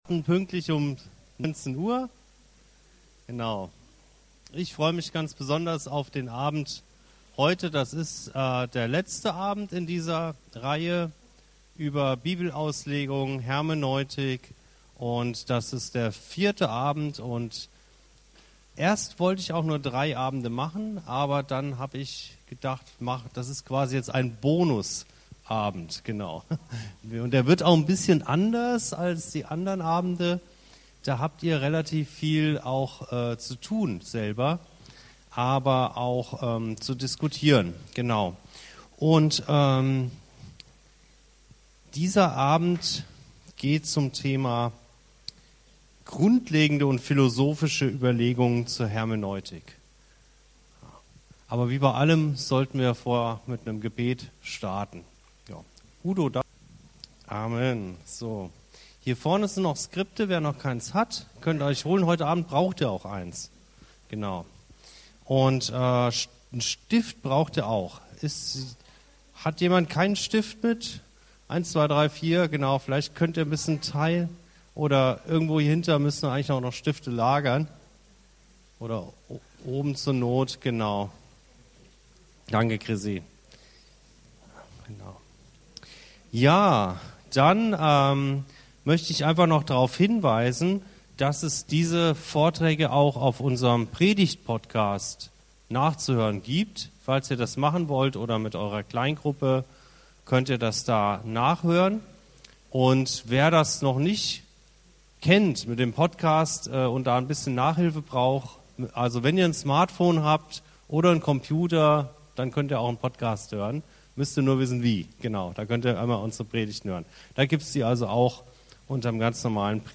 Wie können wir mit Hilfe der "hermeneutischen Spirale" unserer Voreingenommenheit begegnen? Welche Rolle spielt der Heilige Geist bei der Erkenntnis? Dieser Abend ist von zahlreichen Tests und Diskussionen begleitet, daher ist die Folge geschnitten.